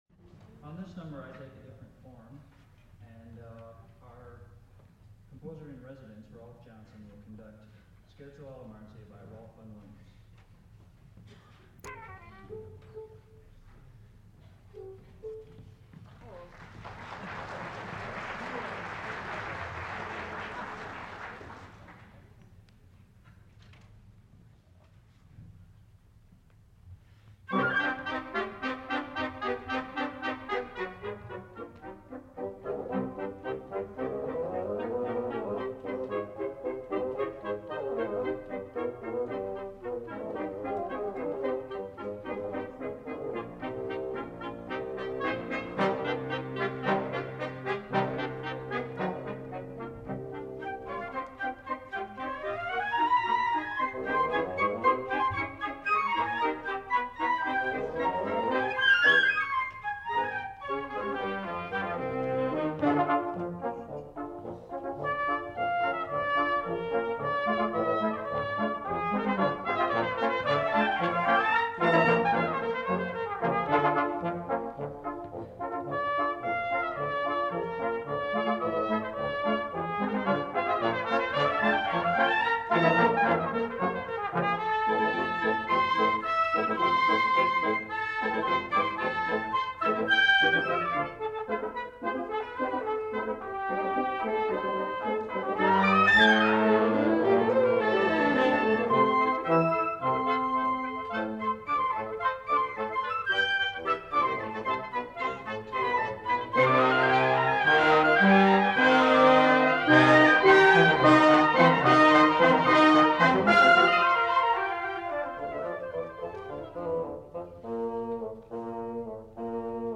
Concert Performance March 26, 1974
using a half-track, 10” reel-to-reel Ampex tape recorder.
Armstrong Auditorium, Sunday at 4:00 PM